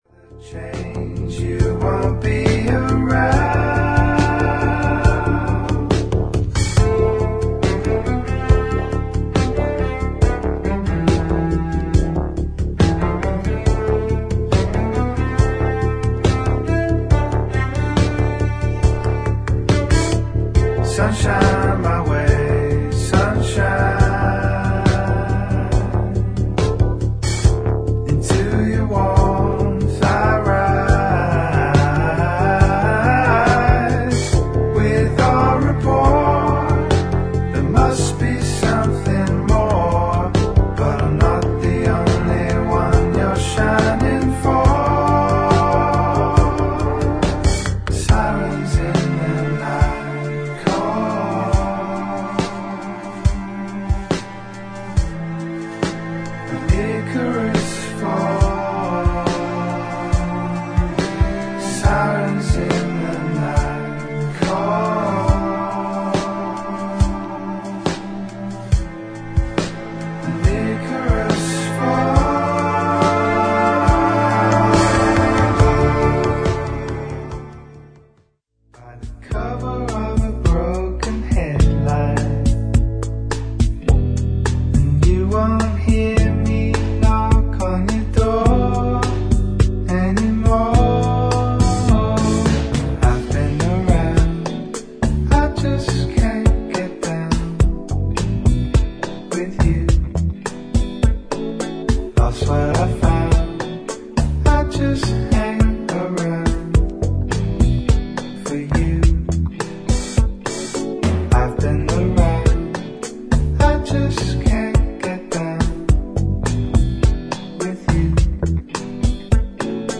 デイドリーミンなサイケデリック感を纏った
フォーキーでブルー・アイド・ソウルな7インチ